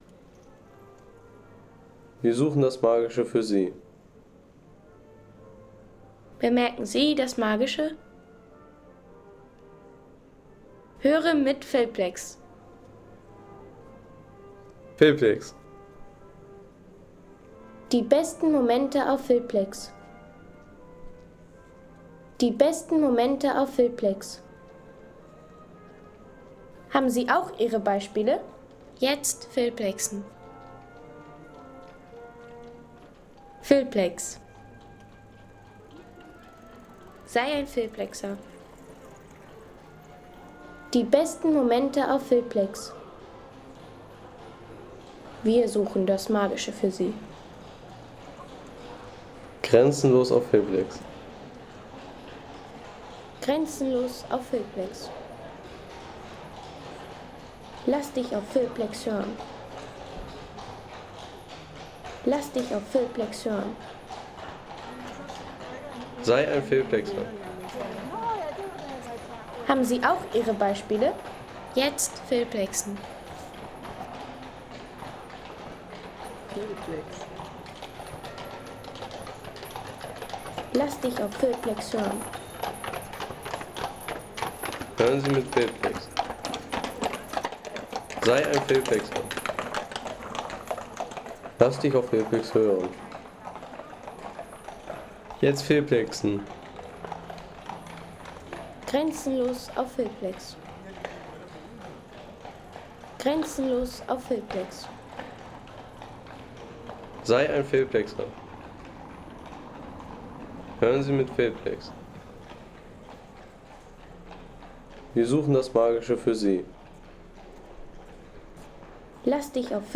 Romantische Kutschenfahrt
Romantische Kutschenfahrt durch die Dresdner Innenstadt.